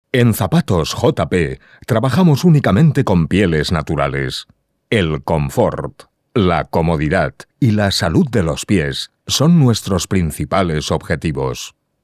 Locutor profesional.
Sprechprobe: Industrie (Muttersprache):
Spanish voice over.